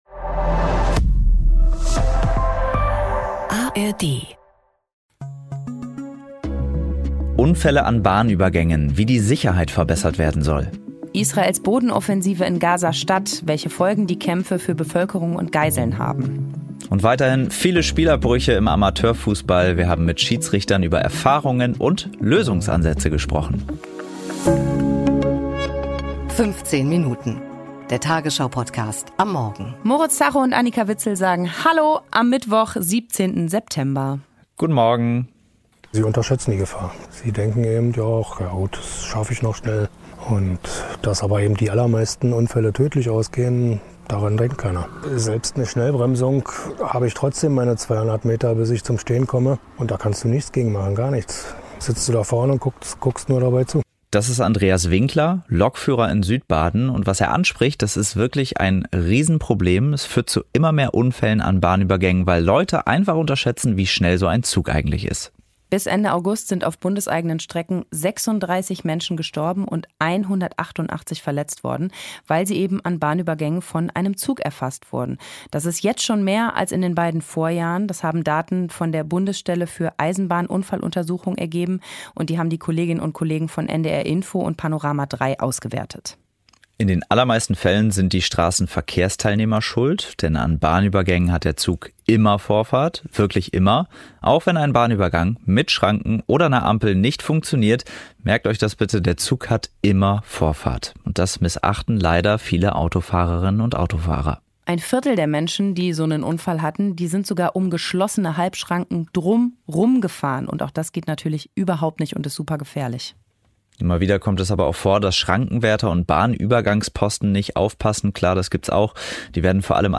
Wir sprechen mit Amateurfußballern und Schiedsrichtern, was sie sich wünschen würden, damit es weniger Stress auf dem Platz gibt.